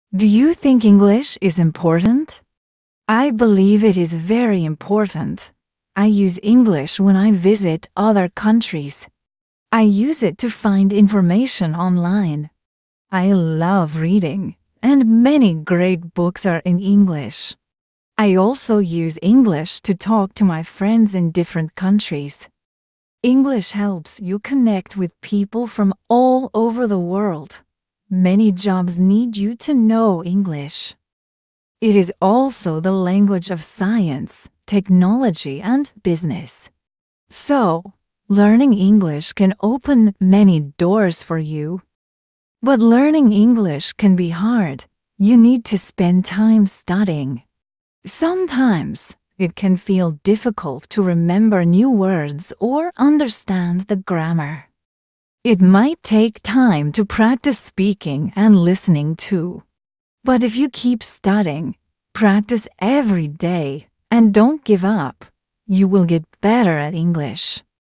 English slow podcast for beginners: